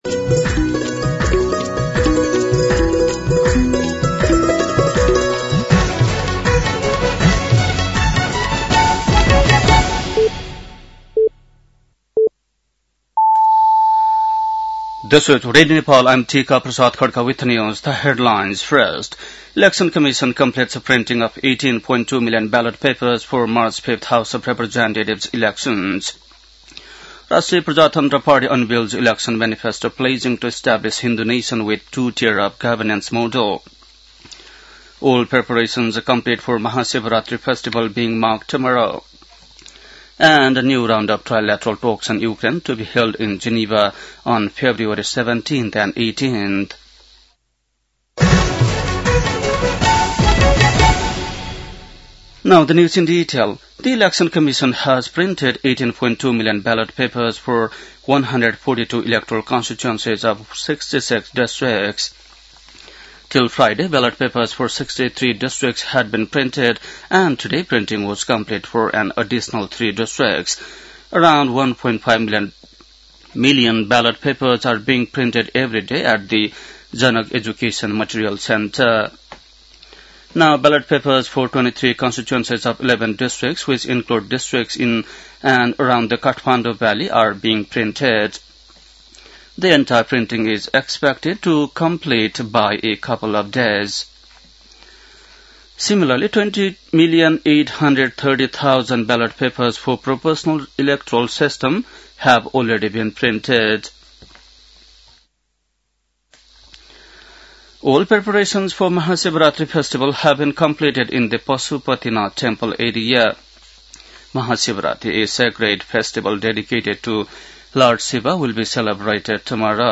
बेलुकी ८ बजेको अङ्ग्रेजी समाचार : २ फागुन , २०८२
8-pm-news-.mp3